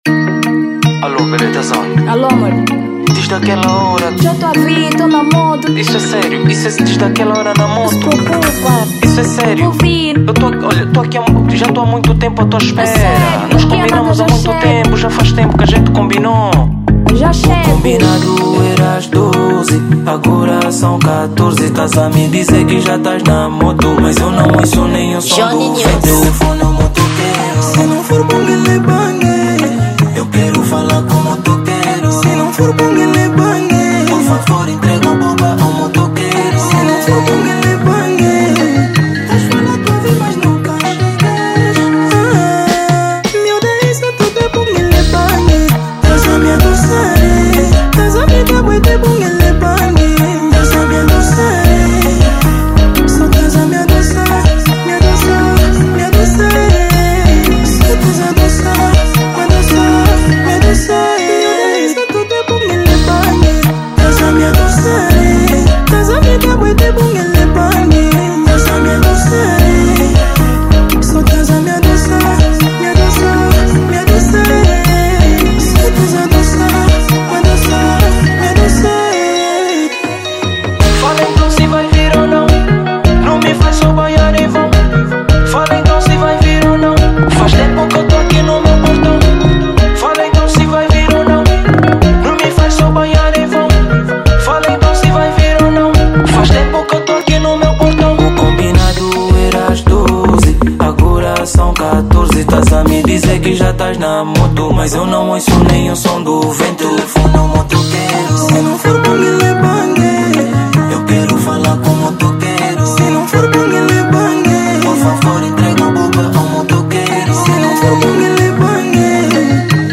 Afro Beat
Gênero: Afro House